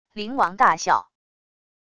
灵王大笑wav音频